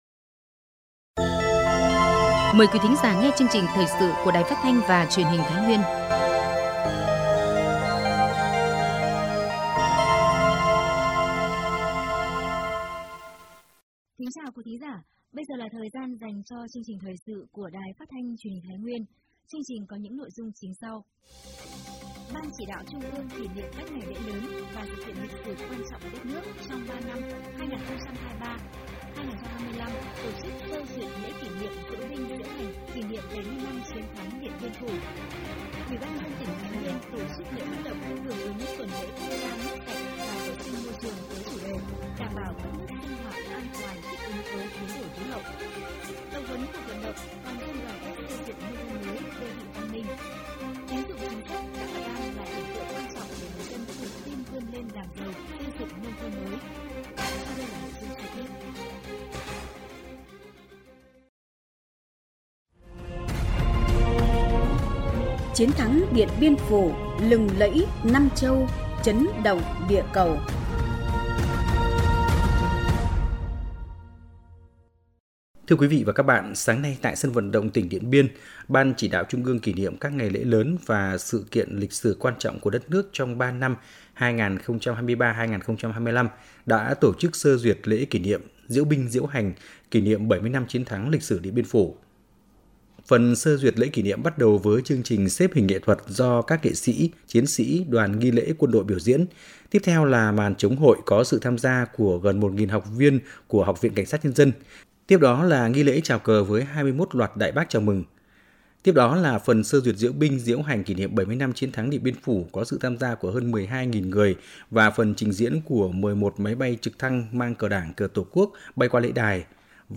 Thời sự tổng hợp Thái Nguyên ngày 09/5/2024